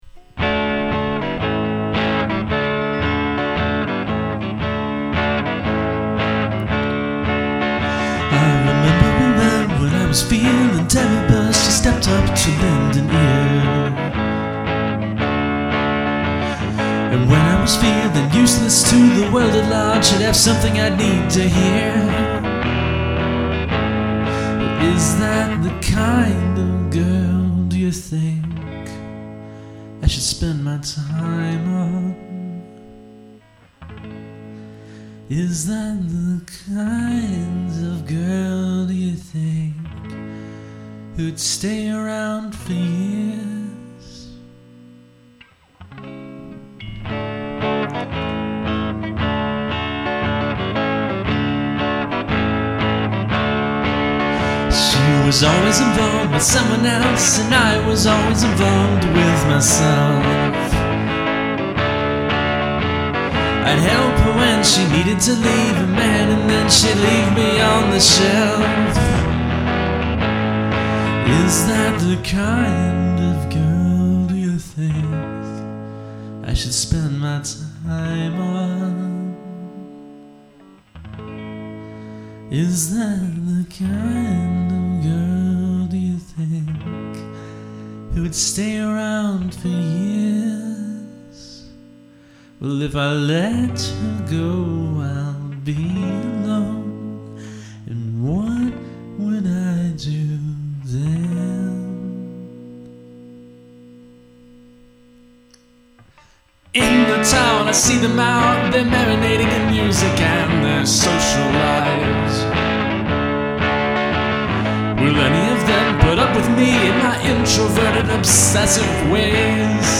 Unfortunately my good guitar has a broken string, so I had to record on my crappy noisy one. So my apologies for the buzz.